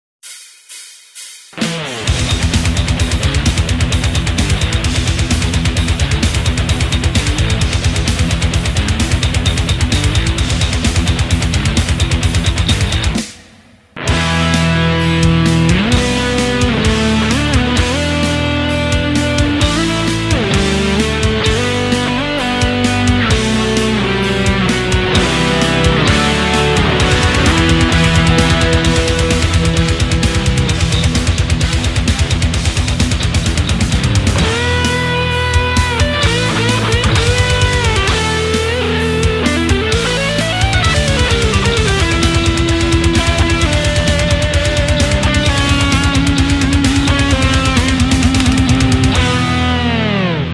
Metal D7 Jam